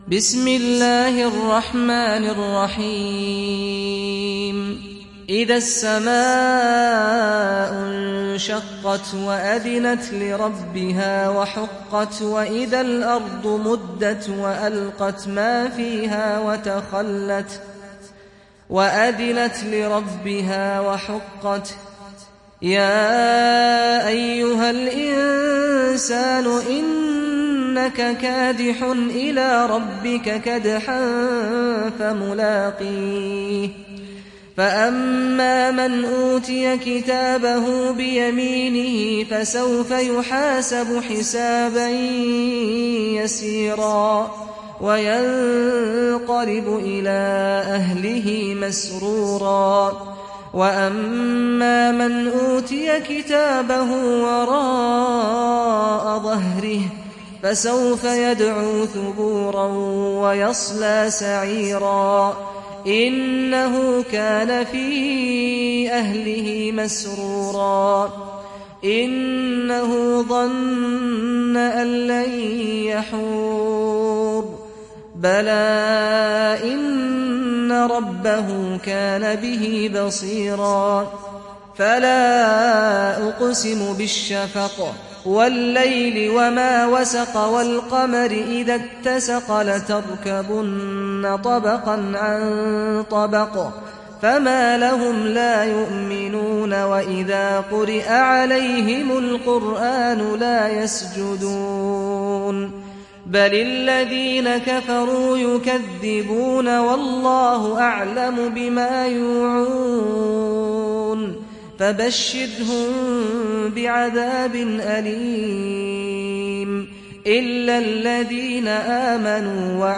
دانلود سوره الانشقاق mp3 سعد الغامدي روایت حفص از عاصم, قرآن را دانلود کنید و گوش کن mp3 ، لینک مستقیم کامل